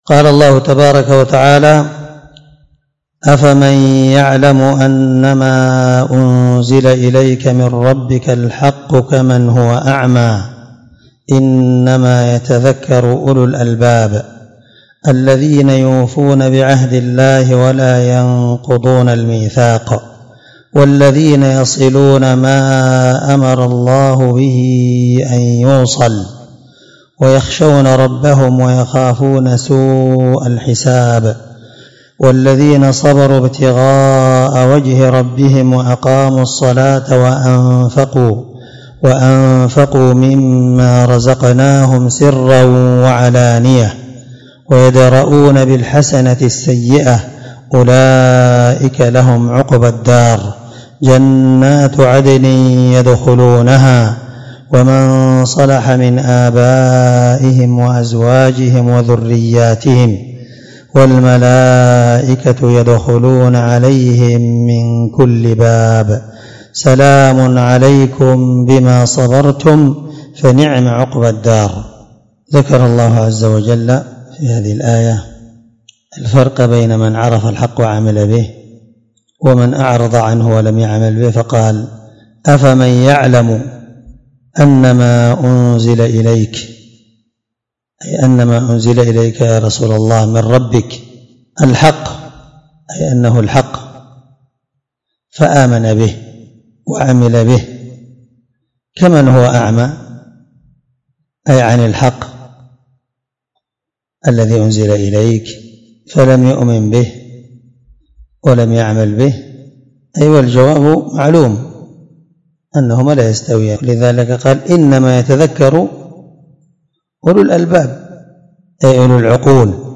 685تفسير السعدي الدرس9 آية (19-24) من سورة الرعد من تفسير القرآن الكريم مع قراءة لتفسير السعدي